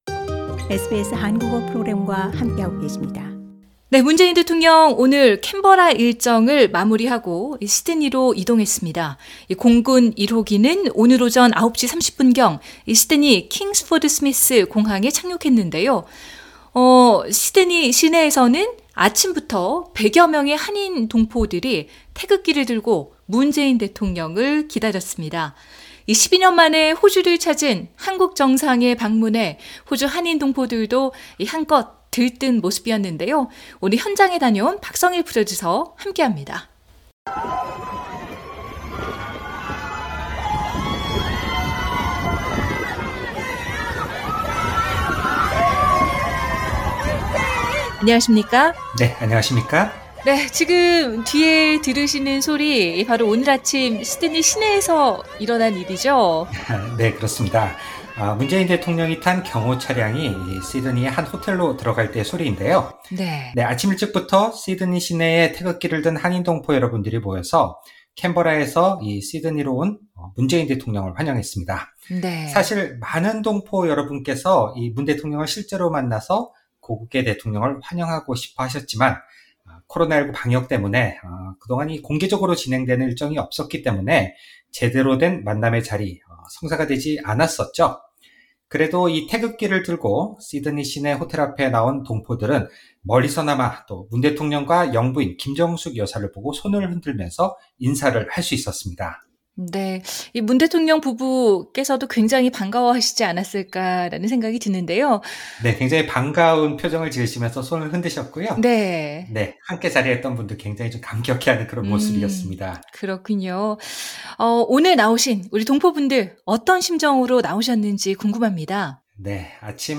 캔버라 일정을 마무리하고 시드니에 온 문재인 대통령을 환영하기 위해 아침부터 약 100여명의 시드니 한인 동포들이 태극기를 들고 시내의 한 호텔 앞에 모였다.